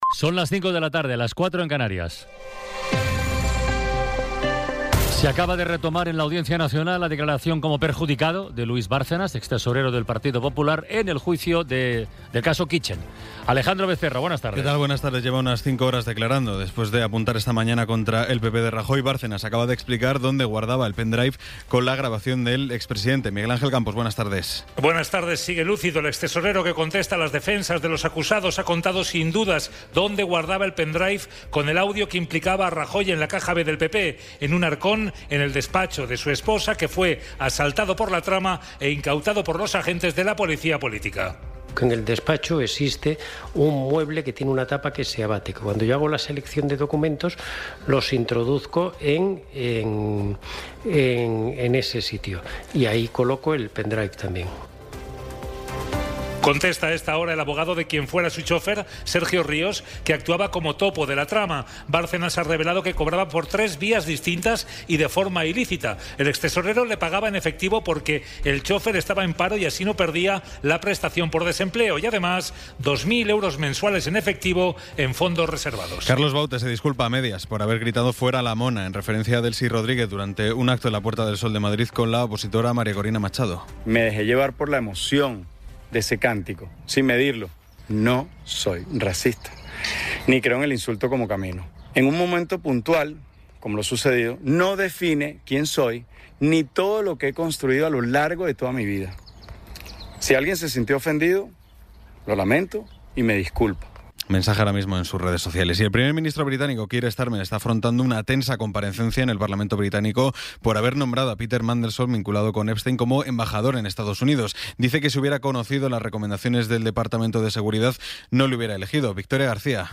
Resumen informativo con las noticias más destacadas del 20 de abril de 2026 a las cinco de la tarde.